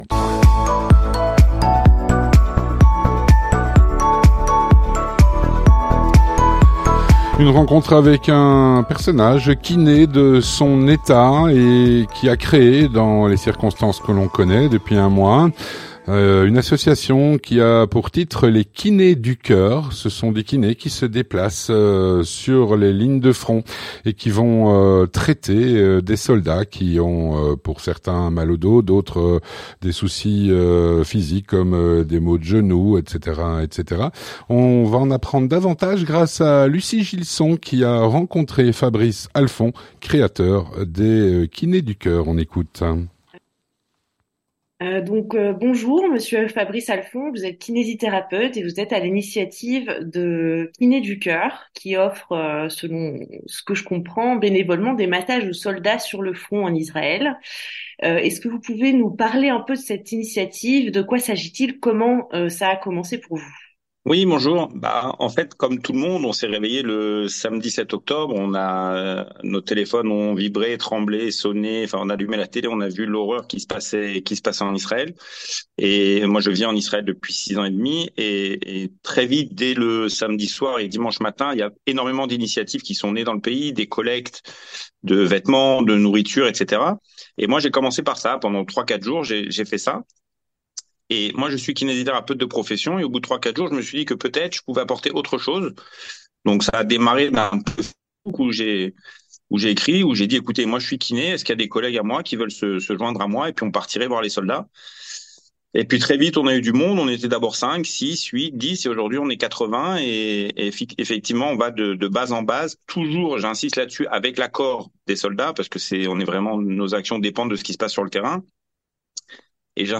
Rencontre - Des kiné dans la guerre.